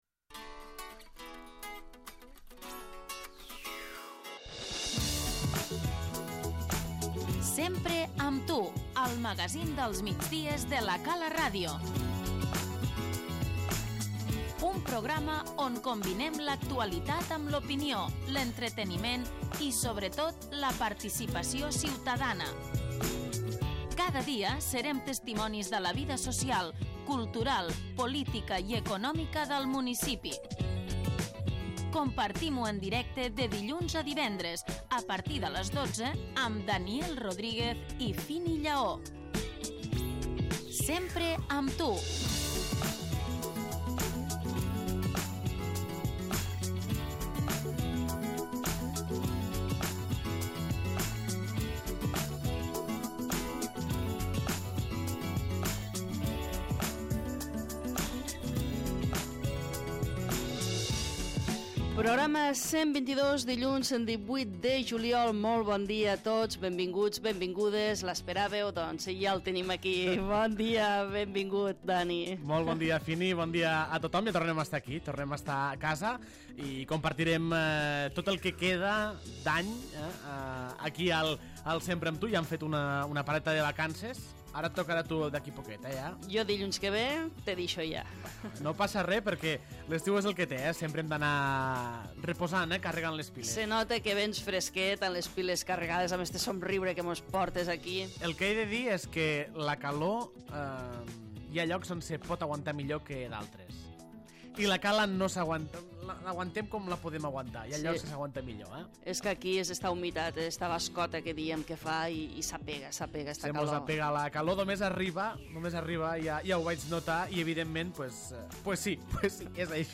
Comença la setmana i torna el magazín dels migdies de La Cala Ràdio, el Sempre amb tu.
L'ENTREVISTA